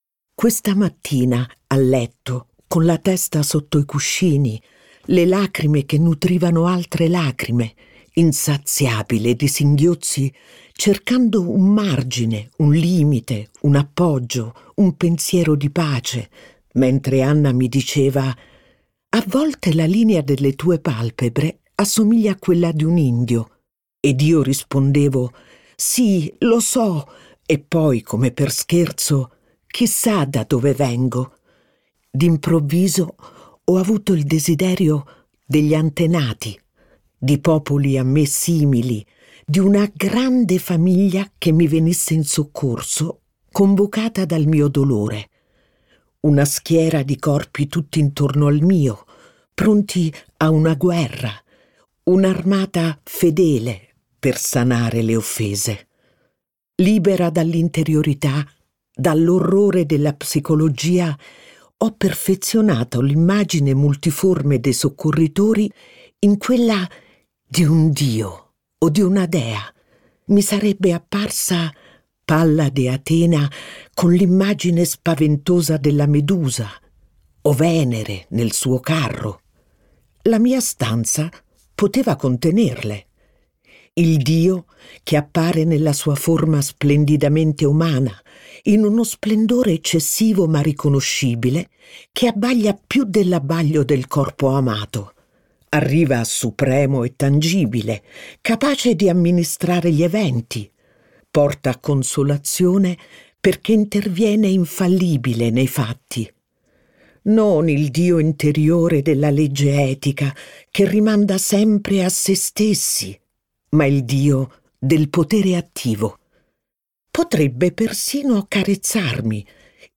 letto da Iaia Forte
Studio di registrazione: 24 Gradi, Roma.